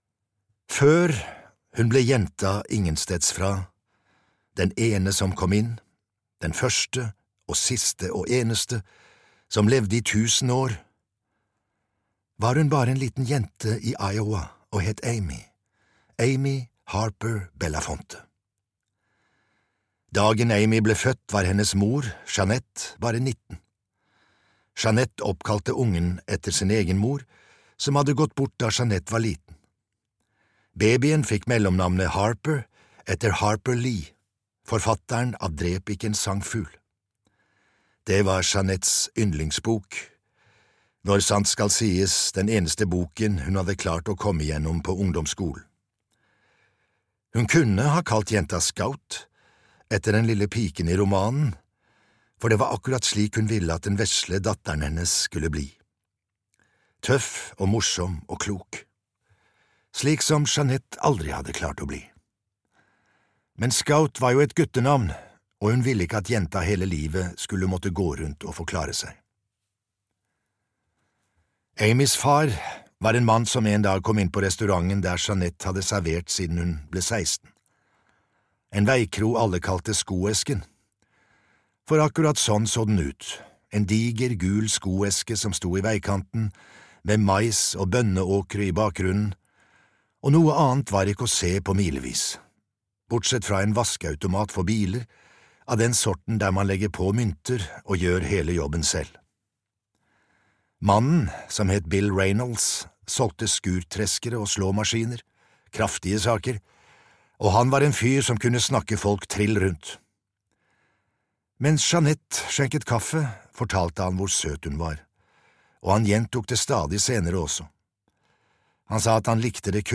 Den første - Del 1 (lydbok) av Justin Cronin